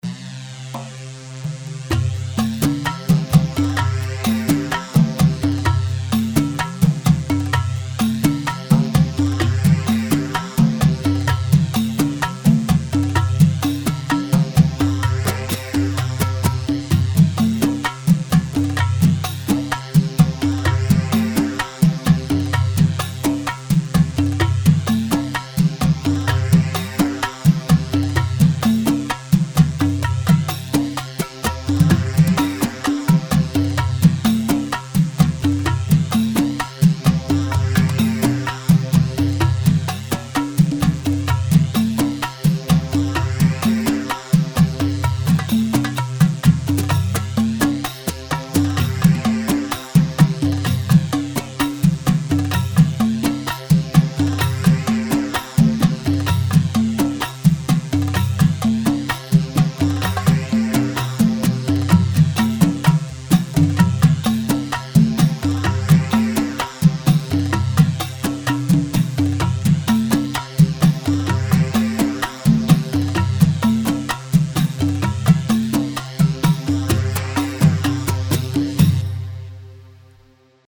Rhumba 4/4 128 رومبا
Rhumba128.mp3